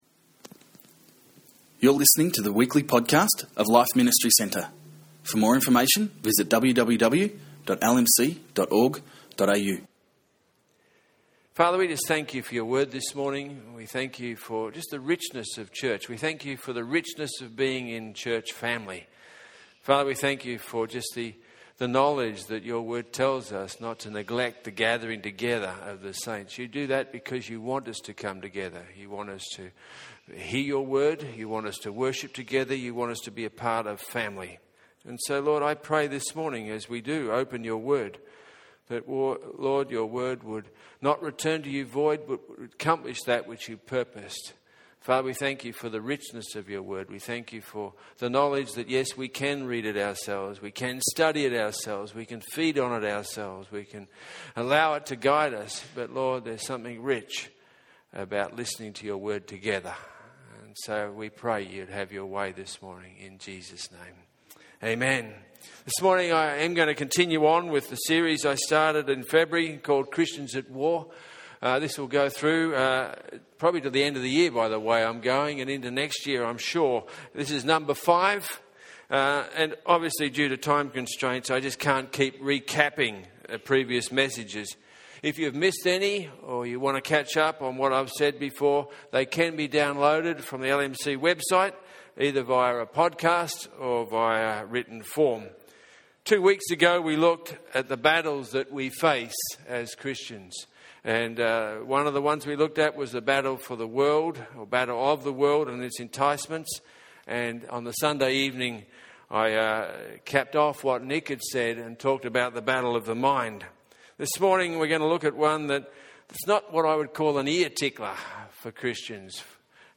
In this message on Christians At War